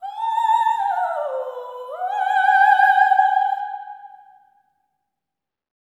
OPERATIC08-L.wav